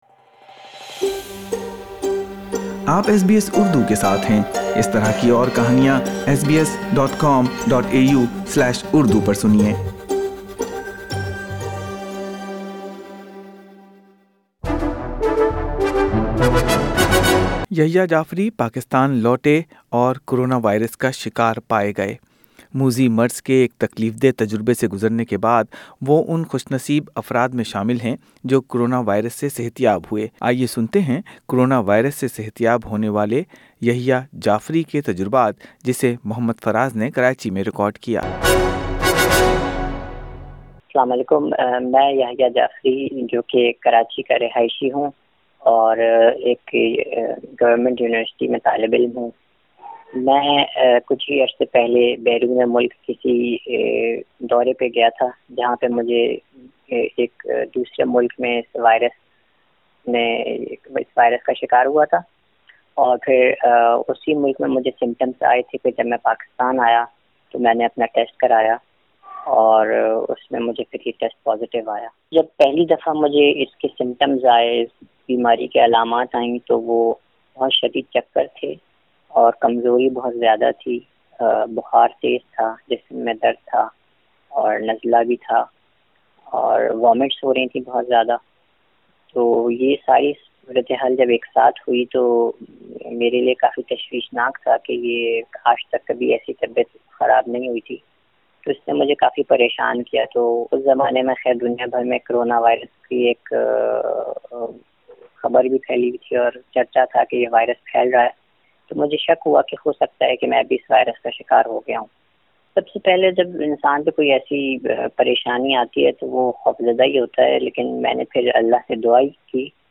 کرانا وائیرس سے صحت یاب ہونے والوں پر کیا گزرتی ہے اس کا احساس صرف ایسے افراد کو ہو سکتا ہے جو ان تجربات سے گزر چکے ہوں۔ ملئیے کرونا وائیرس سے صحت یاب ہونے والے ایک پاکستانی کی کہانی